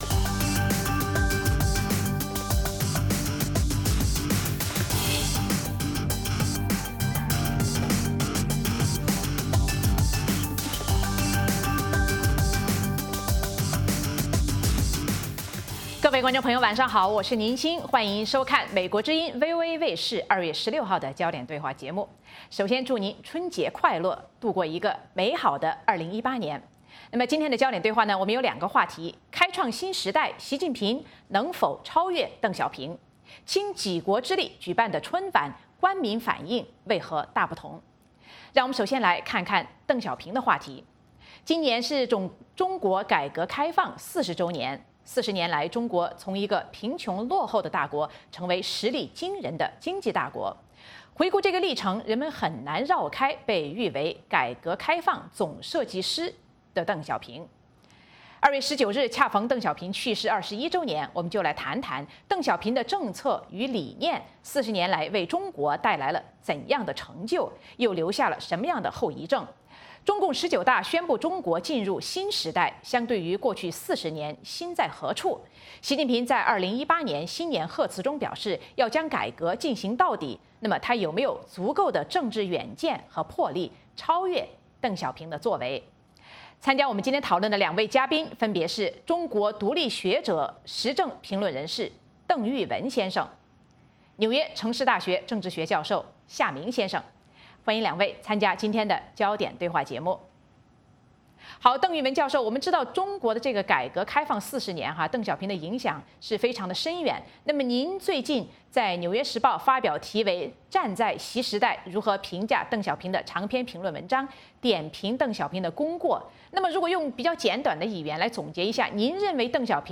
《焦点对话》节目追踪国际大事、聚焦时事热点。邀请多位嘉宾对新闻事件进行分析、解读和评论。或针锋相对、或侃侃而谈。